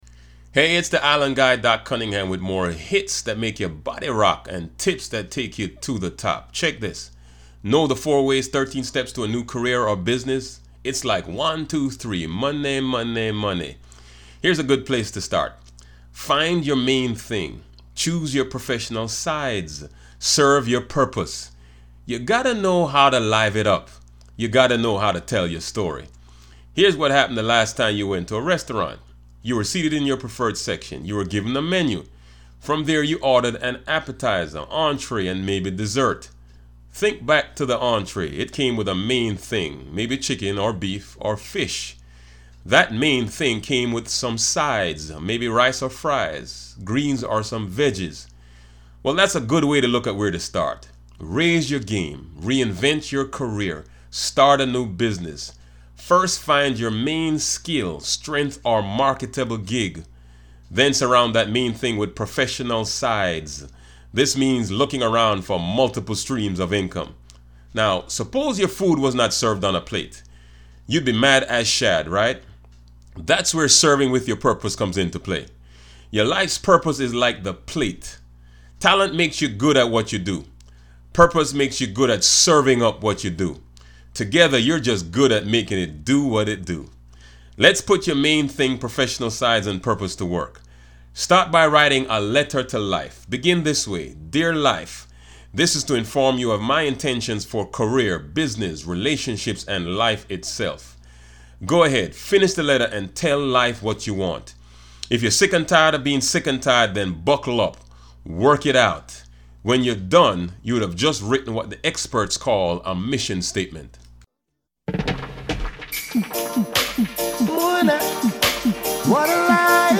Play ‘R & B Flava’ Podcast track here